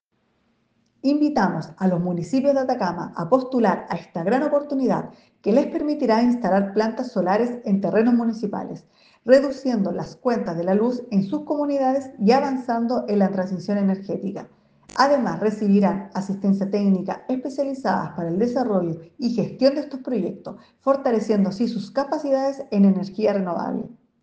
Seremi-de-Energia-PSC.mp3